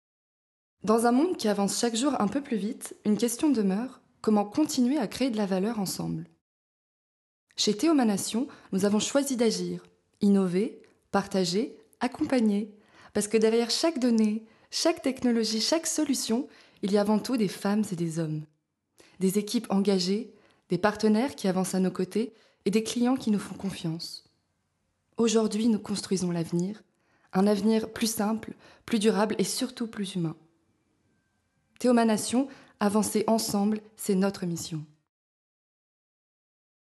PUB VOIX V1
Voix off